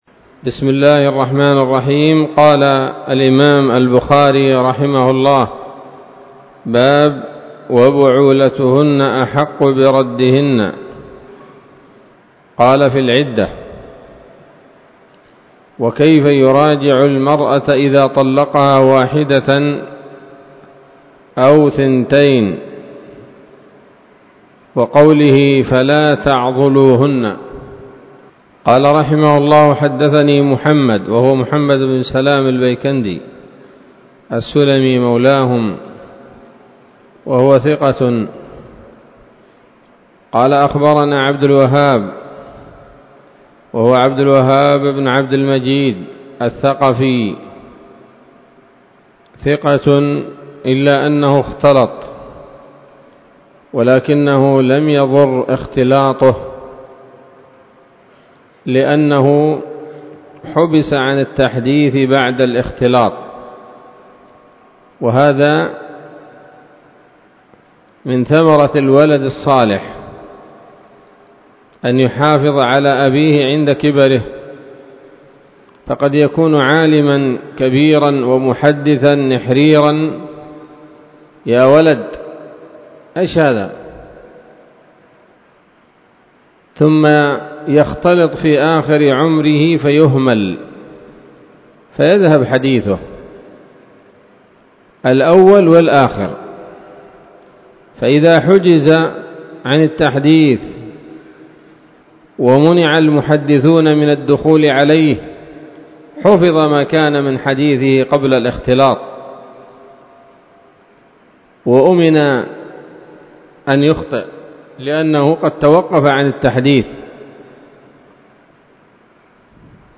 الدرس الرابع والثلاثون من كتاب الطلاق من صحيح الإمام البخاري